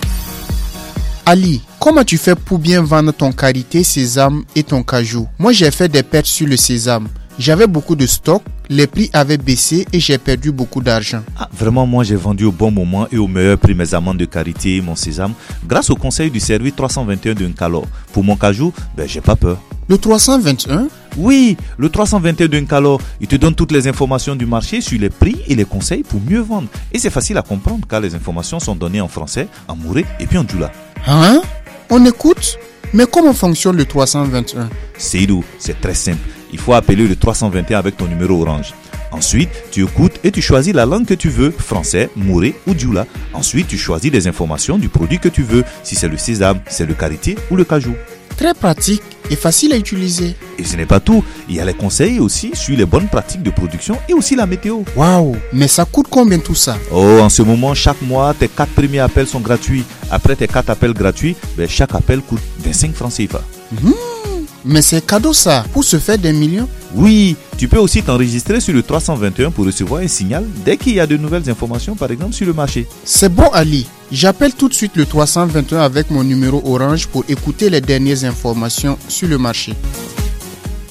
Spot radio pour expliquer le 321 / N'kalô Burkina: l'info agri sur votre mobile !